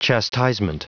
Prononciation du mot chastisement en anglais (fichier audio)